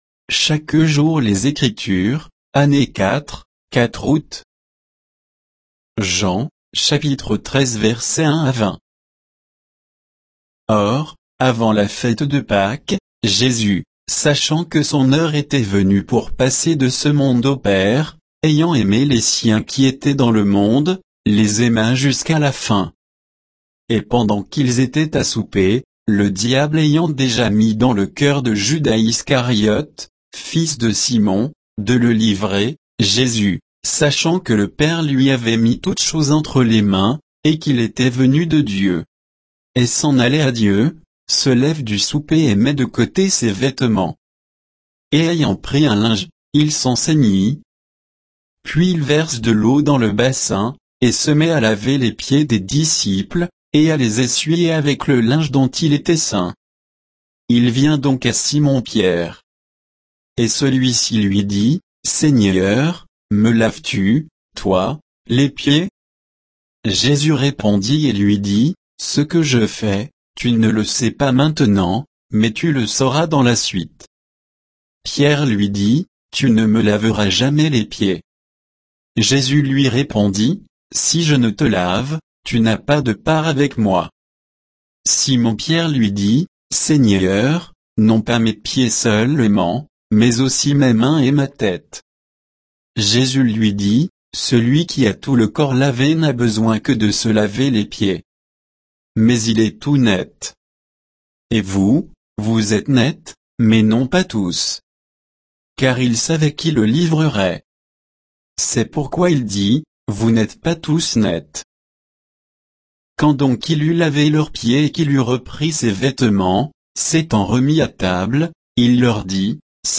Méditation quoditienne de Chaque jour les Écritures sur Jean 13